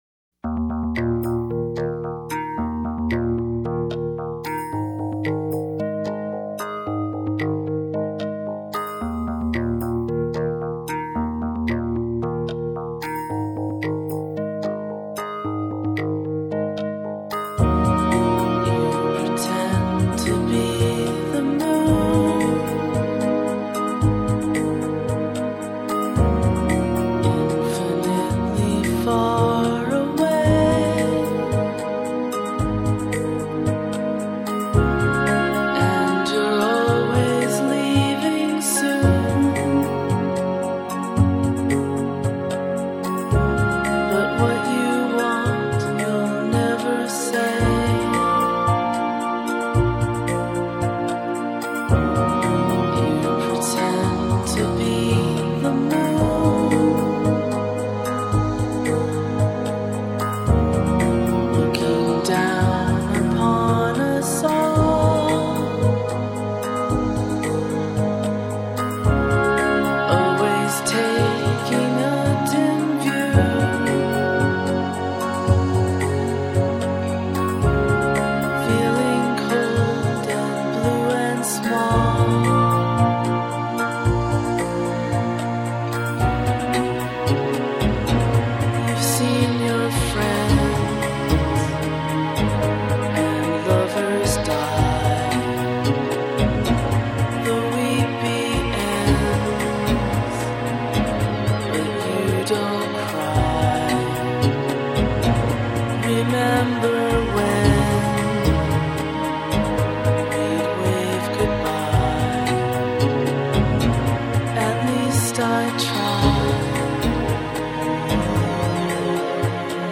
Her voice fits the music and lyrics so beautifully.
is so melty and hush hush